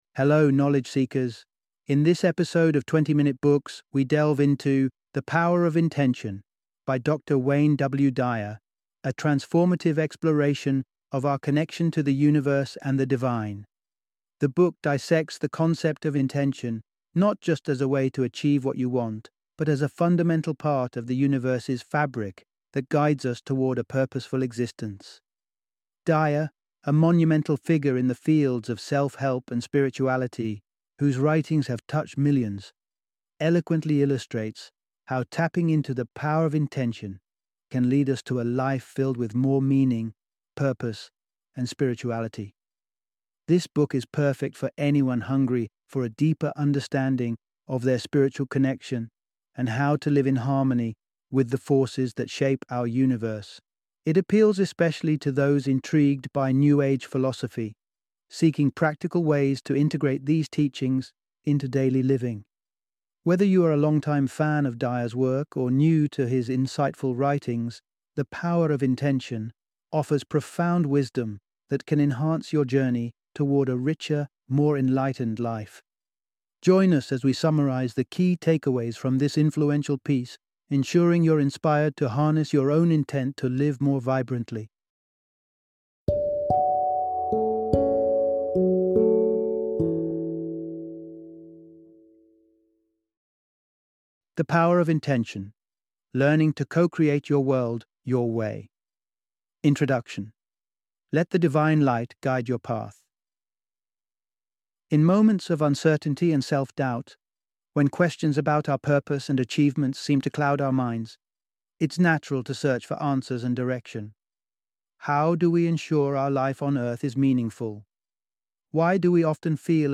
The Power of Intention - Audiobook Summary